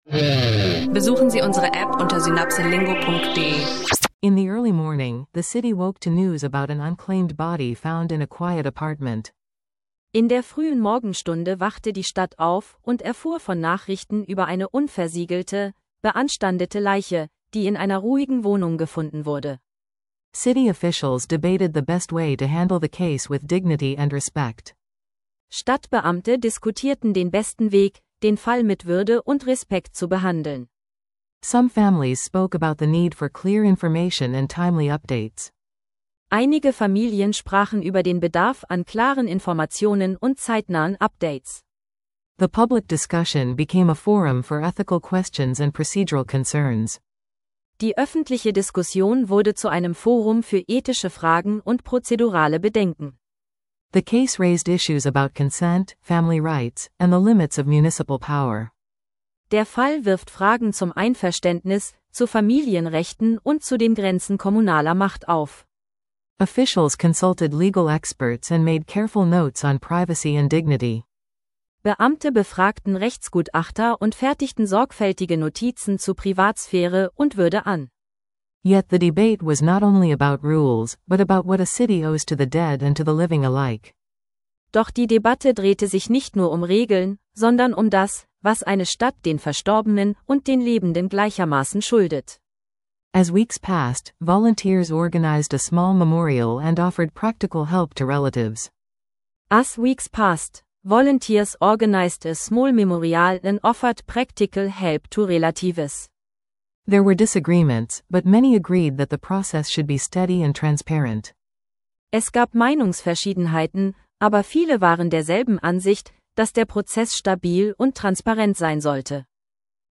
SynapseLingo: Englisch lernen – Dialog über Ethik, Transparenz und Würde | Unclaimed Body Controversy